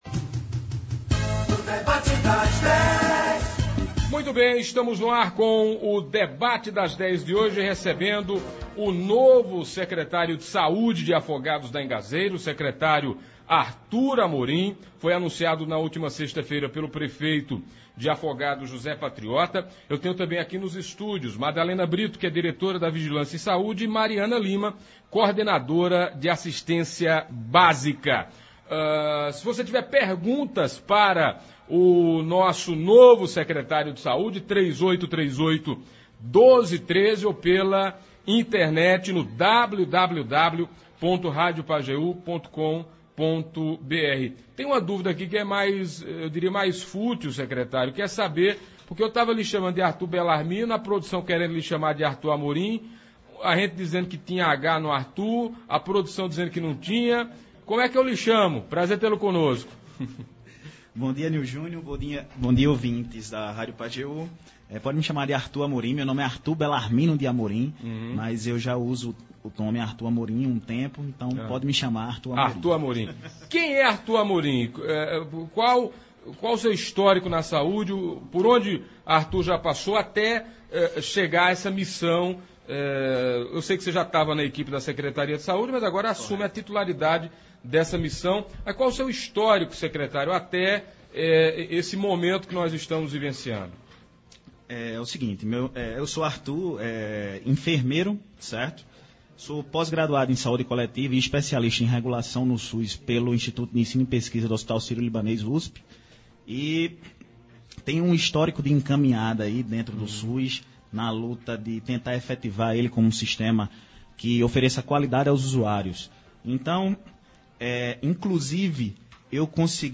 Hoje (05), nos estúdios da Pajeú, o novo secretario de saúde de Afogados da Ingazeira, Artur Amorim, se apresentou a população do município, traçou seu perfil, falou sobre as prioridades e disse como será a sua gestão a frente da Secretaria.